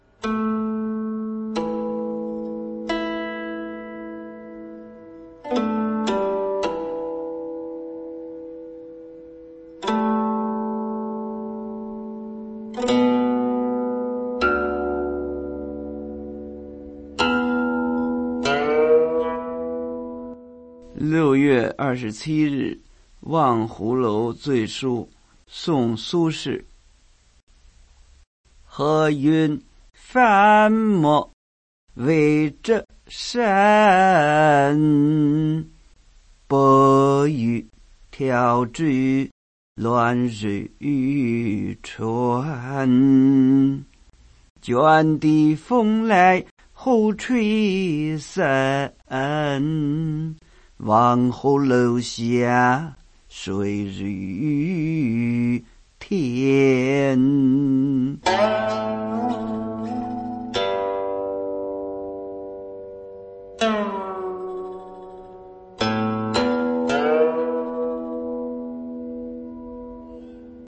吟哦